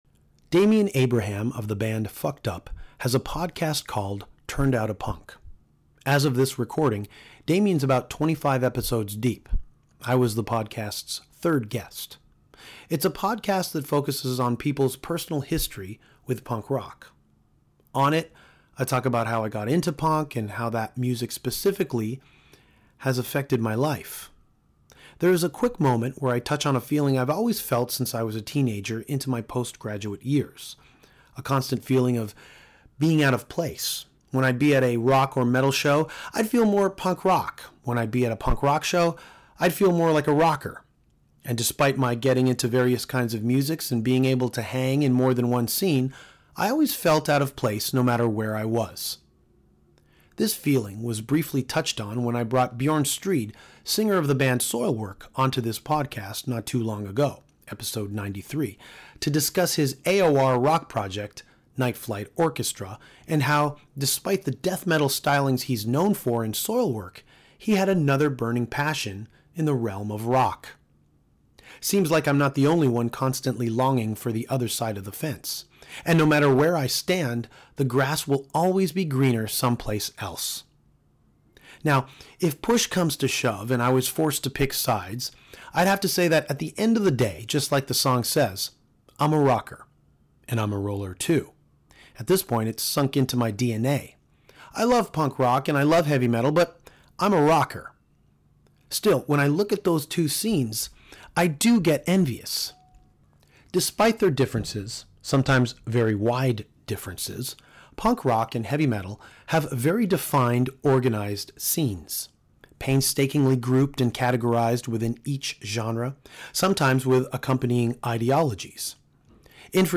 Cam Pipes, vocalist for 3 Inches Of Blood, met up with Danko to chat about Dungeons & Dragons, Metallica’s Through The Never film and Cam’s Rush cover band – Xanadudes.